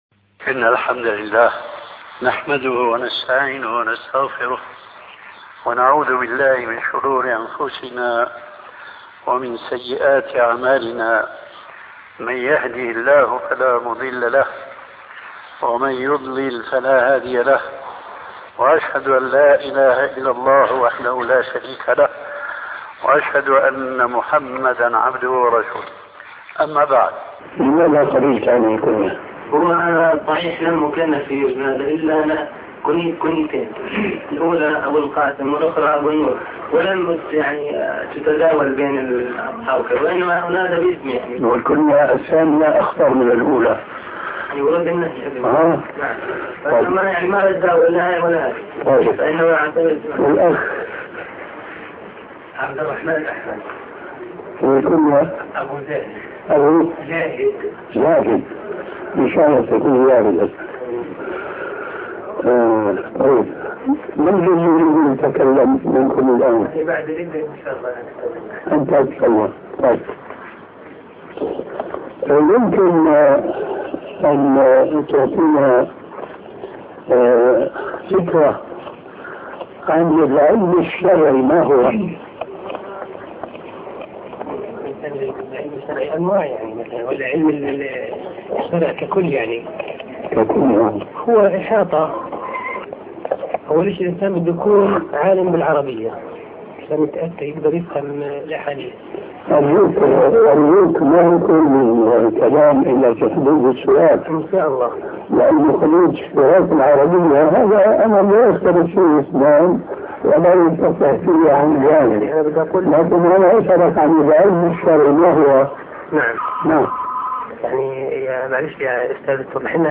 محاضرة هل لله مكان ؟